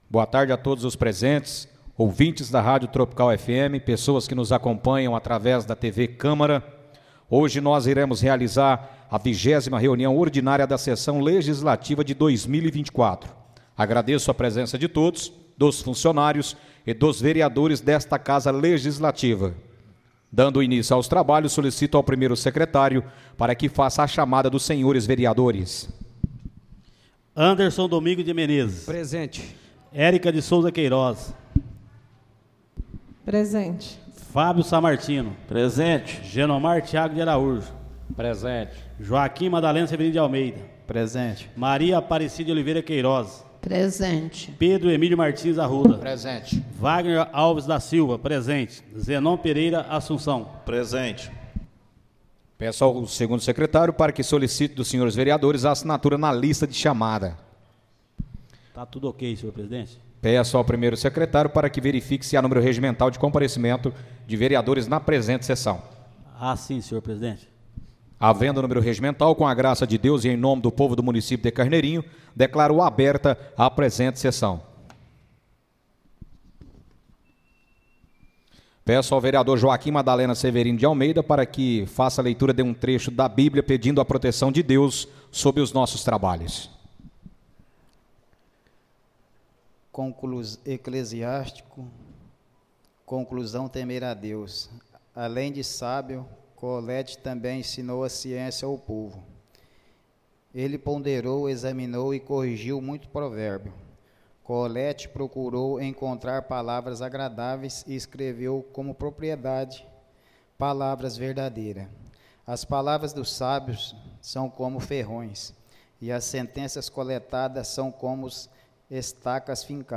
Áudio da 20.ª reunião ordinária de 2024, realizada no dia 02 de Dezembro de 2024, na sala de sessões da Câmara Municipal de Carneirinho, Estado de Minas Gerais.